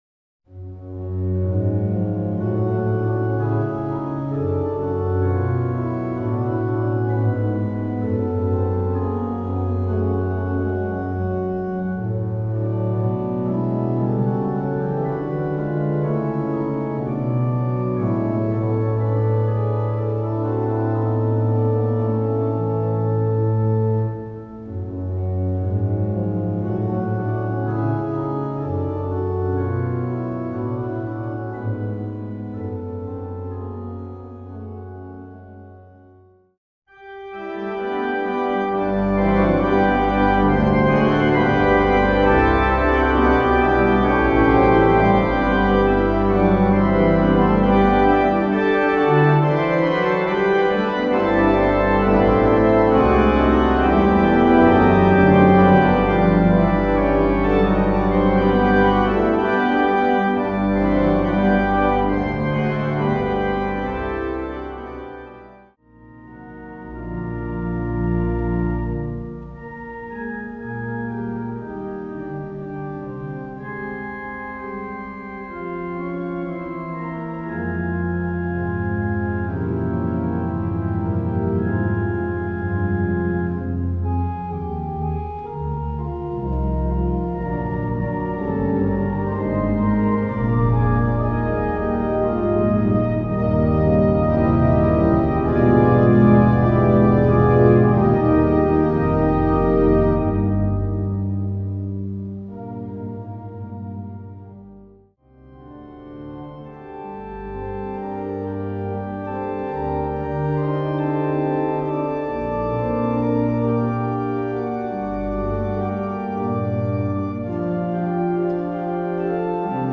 for organ
Estonian Music